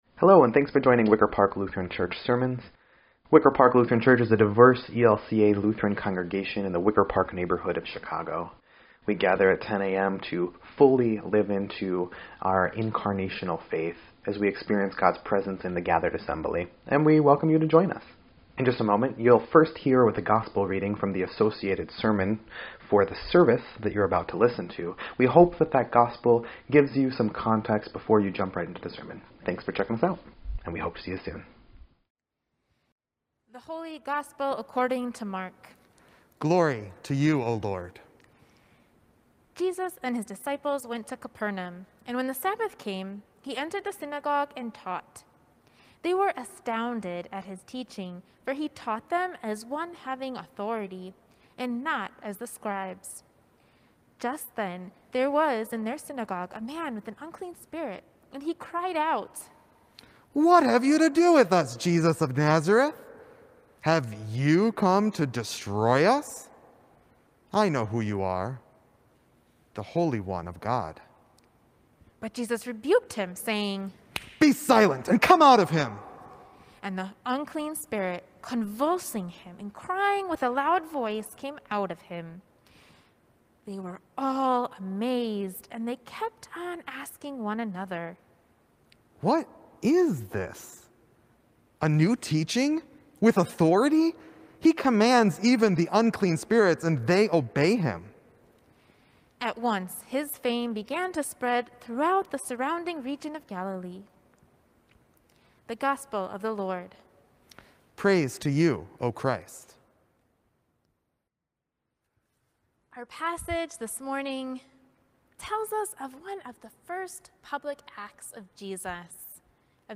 1.31.21-Sermon_EDIT.mp3